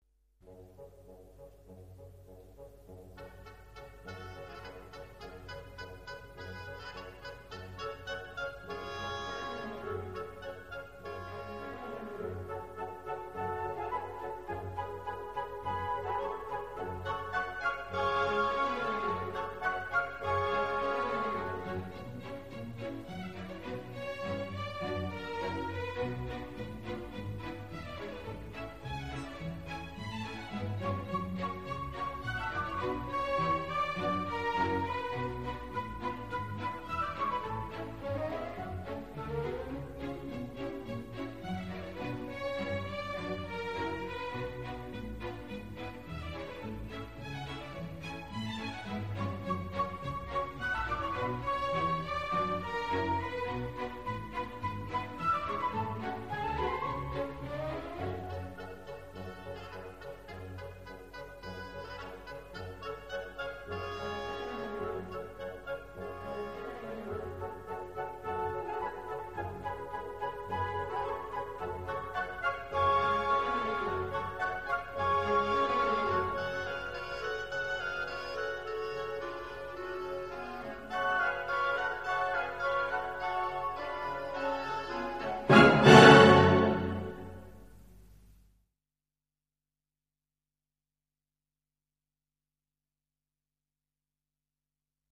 Танец маленьких лебедей - Чайковский П.И. Классическая музыка величайшего композитора для взрослых и детей.